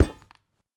Minecraft Version Minecraft Version snapshot Latest Release | Latest Snapshot snapshot / assets / minecraft / sounds / block / vault / step5.ogg Compare With Compare With Latest Release | Latest Snapshot
step5.ogg